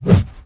swing2.wav